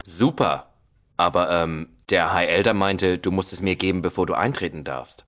Fallout: Audiodialoge